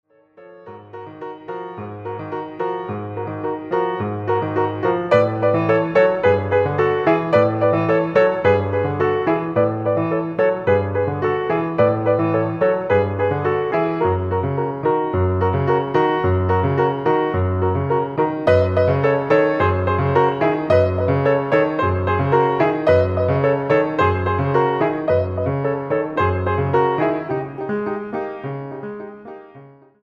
cello, voice, and percussion
transverse flute and percussion